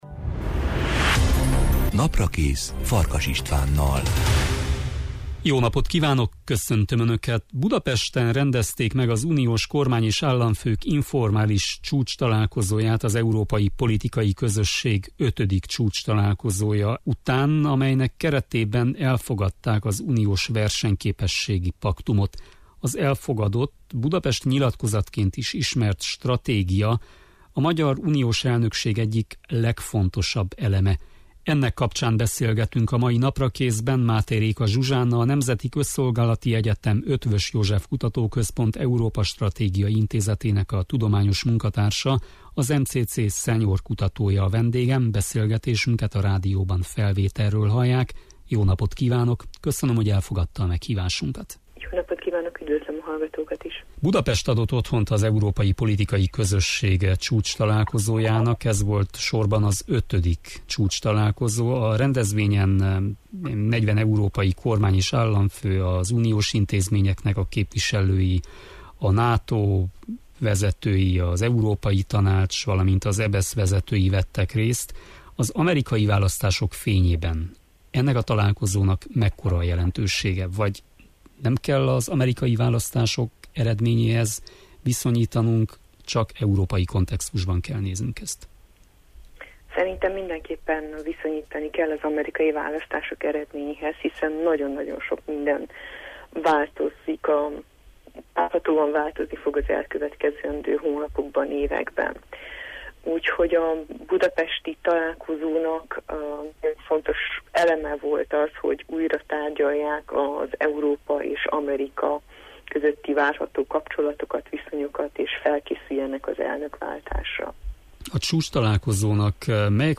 Ennek kapcsán beszélgetünk a mai Naprakészben